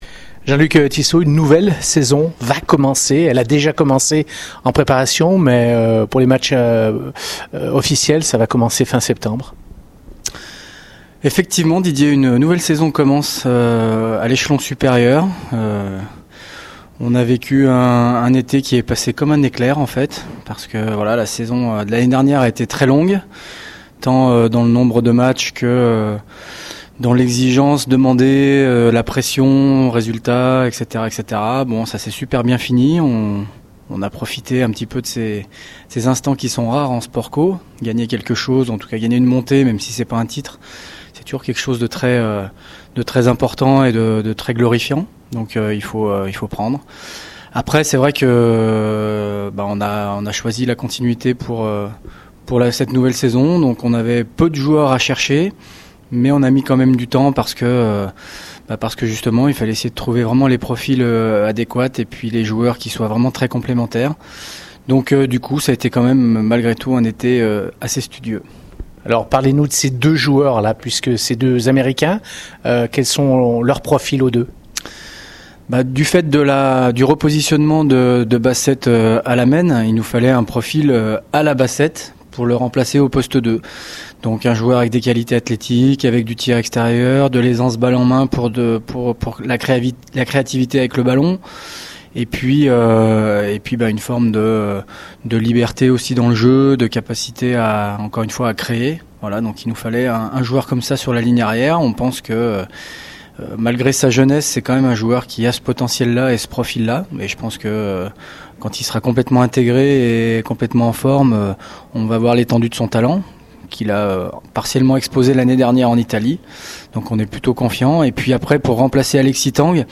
On l’écoute au micro Radio Scoop